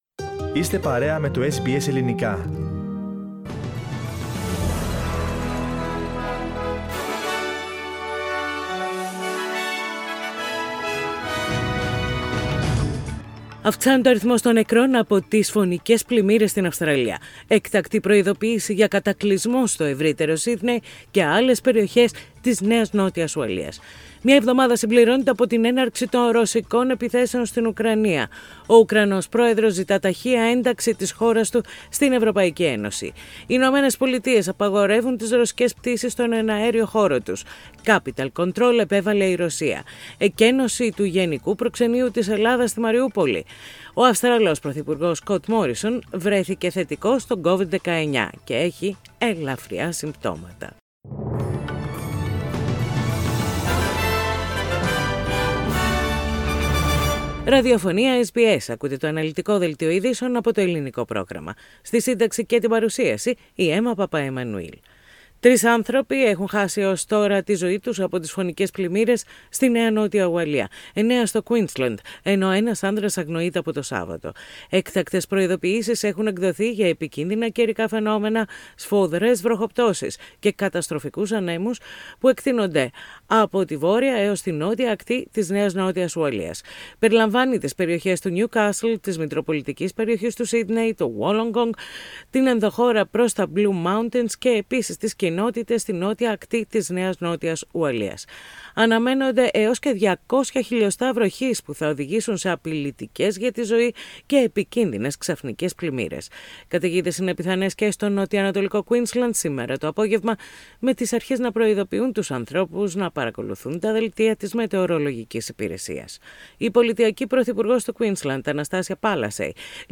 Main bulletins with news from Australia, Greece, Cyprus and the world.